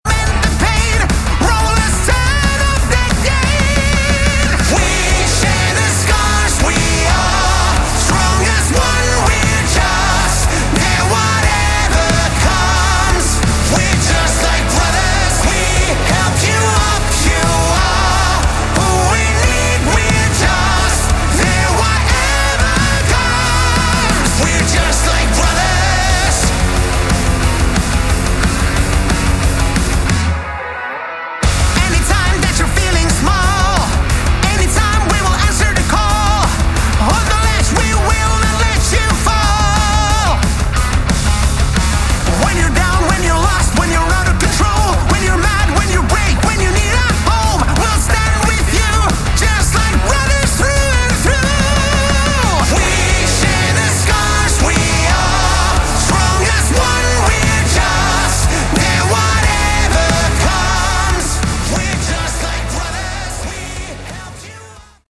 Category: Melodic Rock
vocals
guitars
drums
bass